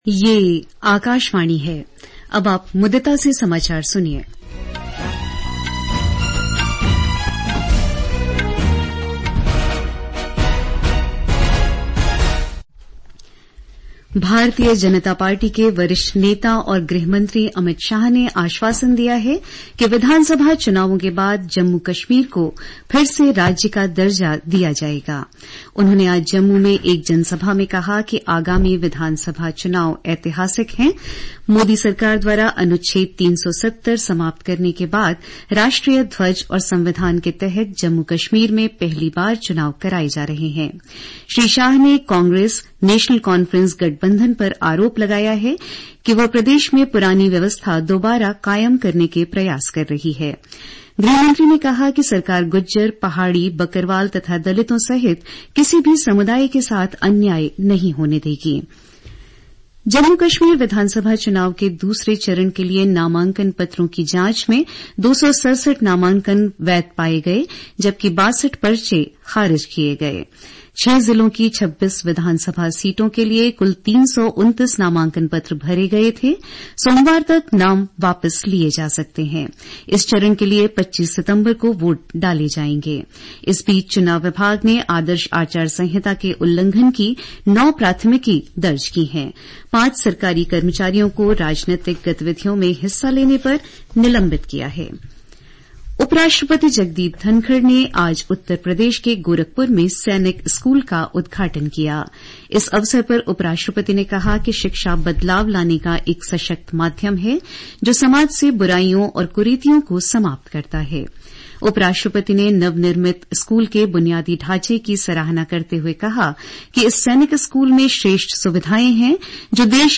National Bulletins